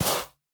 Minecraft Version Minecraft Version snapshot Latest Release | Latest Snapshot snapshot / assets / minecraft / sounds / block / soul_sand / step2.ogg Compare With Compare With Latest Release | Latest Snapshot